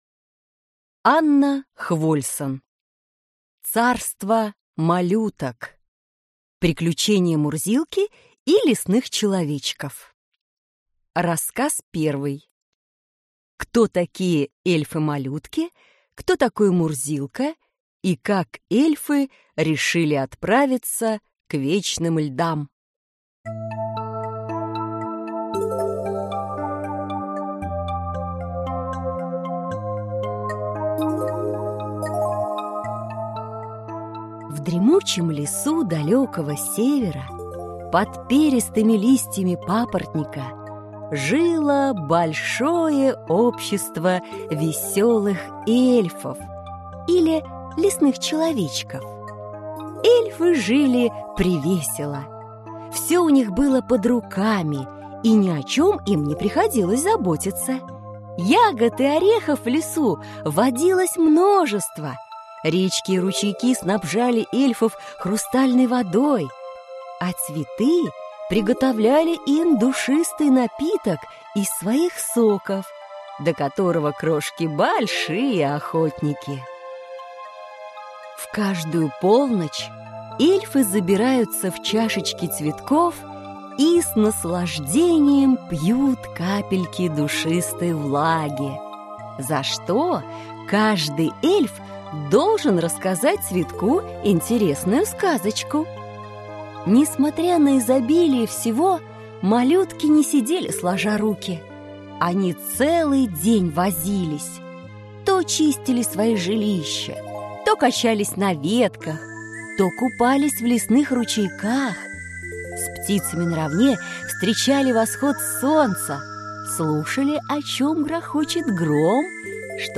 Аудиокнига Приключения Мурзилки и лесных человечков | Библиотека аудиокниг